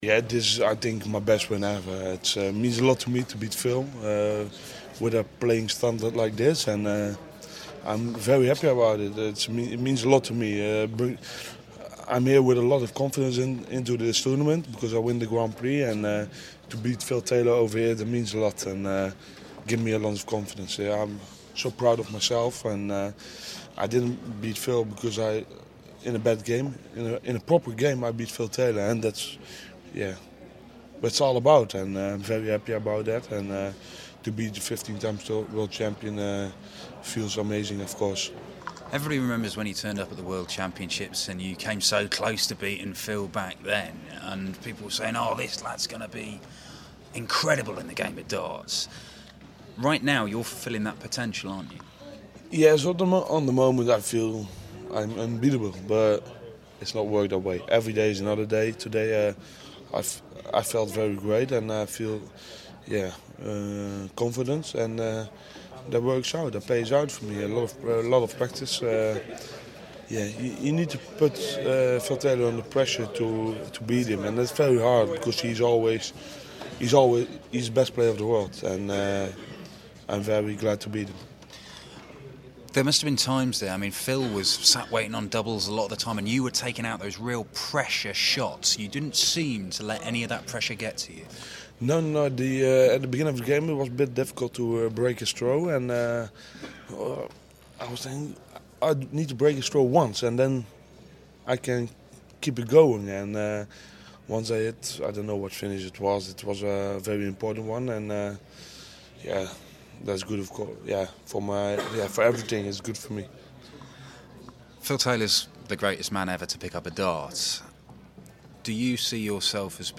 William Hill GSOD - van Gerwen Interview (Last 16)